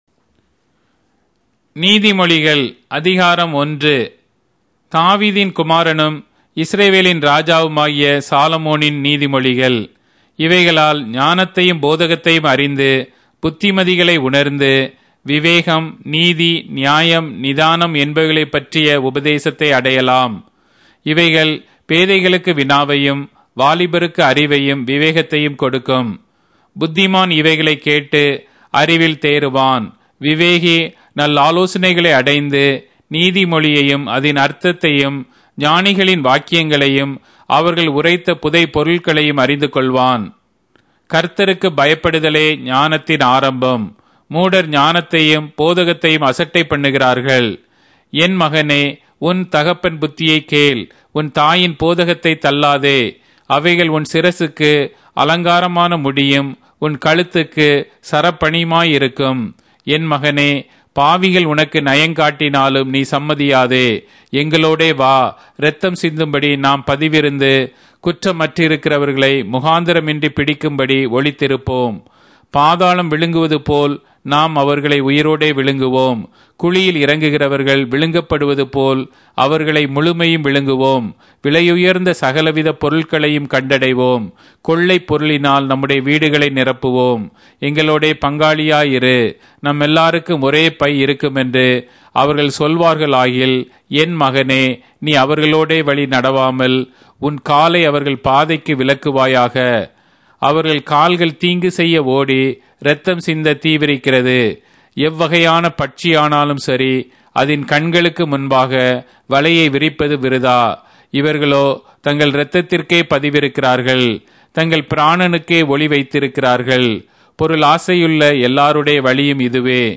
Tamil Audio Bible - Proverbs 4 in Tov bible version